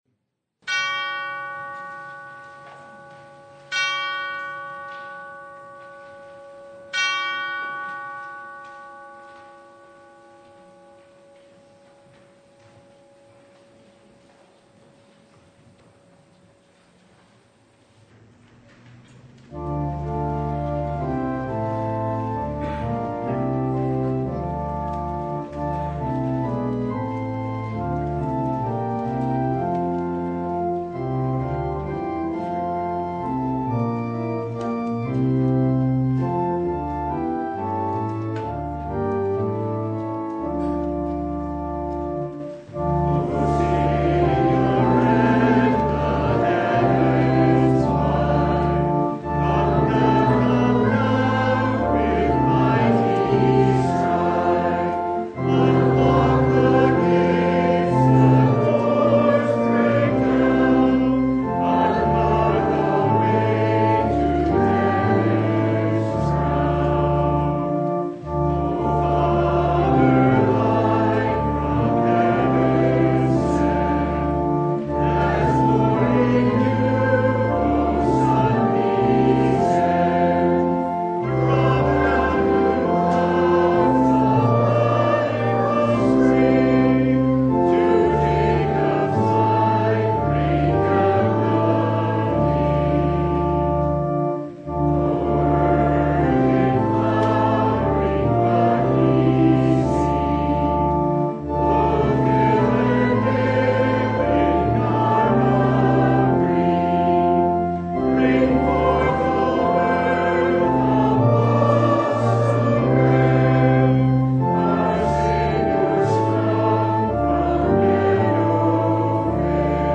Service Type: Sunday
Download Files Bulletin Topics: Full Service « Advent Noon (December 11, 2024) Are You the One?